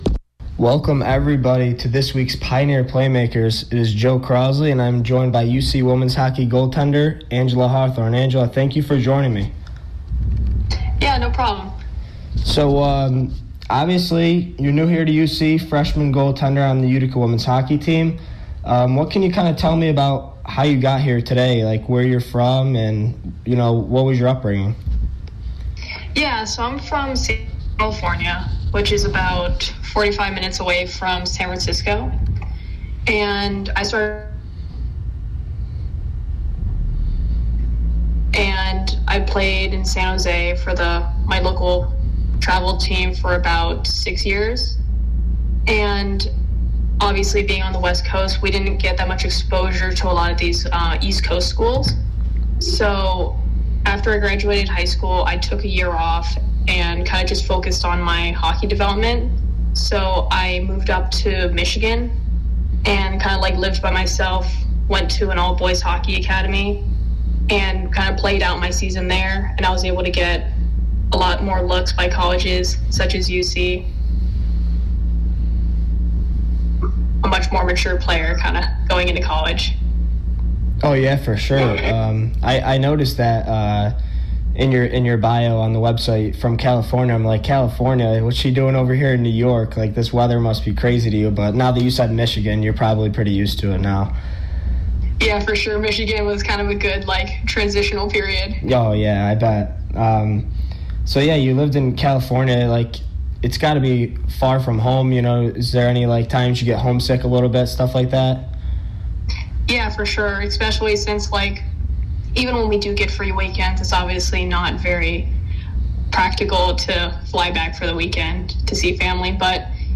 WPNR 90.7 FM Interview